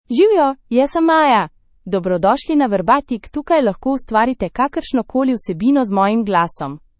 Maya — Female Slovenian AI voice
Maya is a female AI voice for Slovenian (Slovenia).
Voice sample
Listen to Maya's female Slovenian voice.
Female